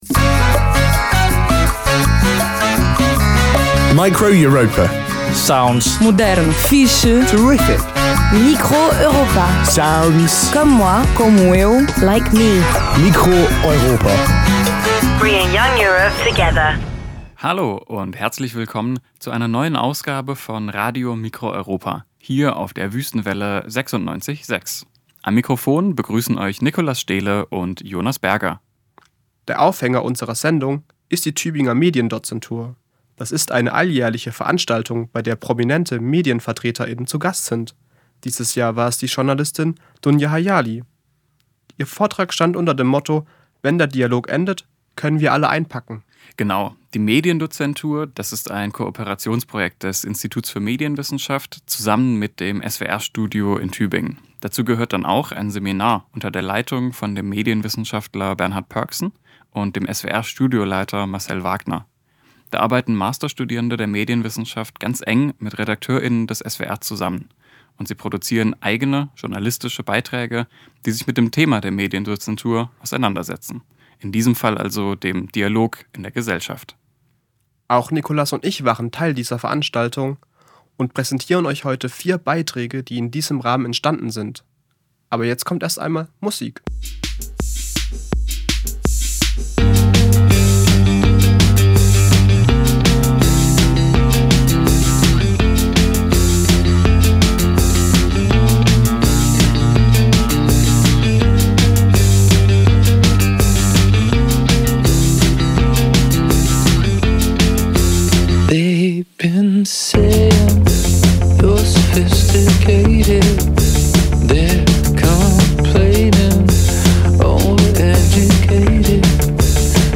Live-Aufzeichnung, geschnitten